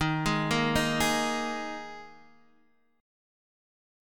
D#sus4 chord